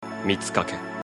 Like Tasuki, Mitsukake's name is pronounced with a short middle vowel, and his final syllable is sounded, "
Mits'ka-keh".
mitsukake_pronunc.mp3